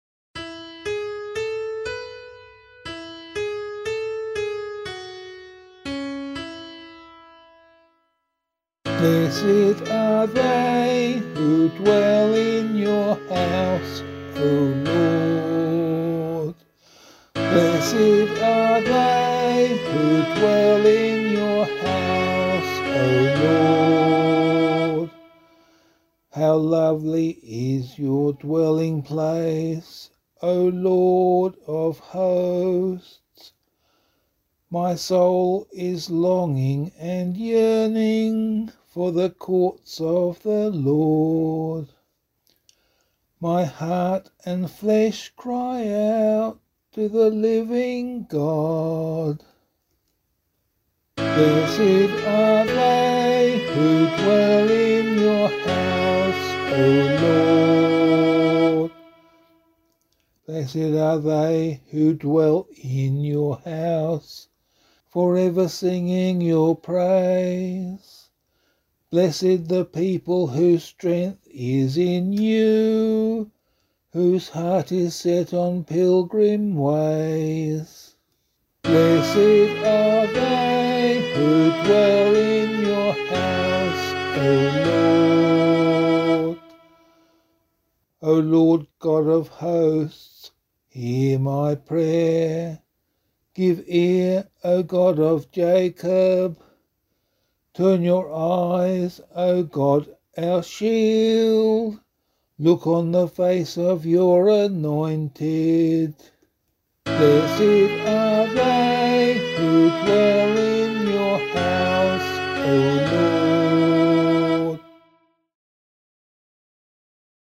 007 Holy Family Psalm C [APC - LiturgyShare + Meinrad 8] - vocal.mp3